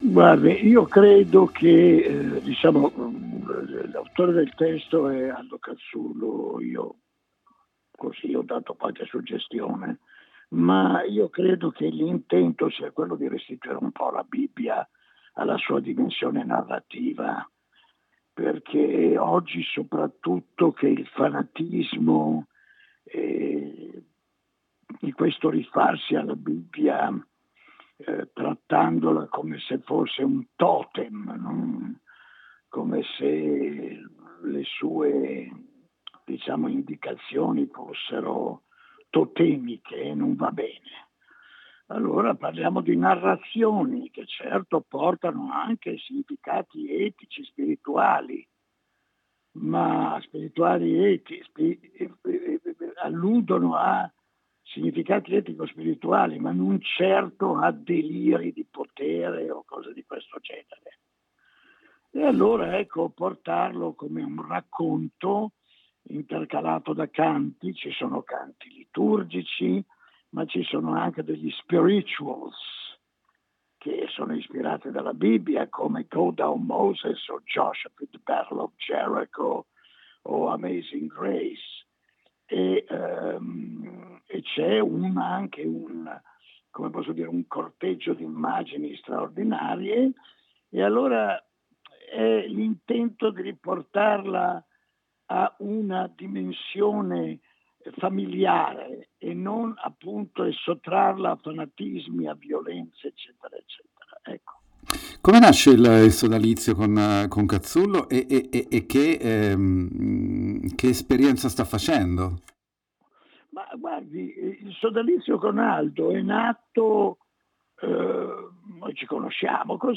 INTERVISTA CON MONI OVADIA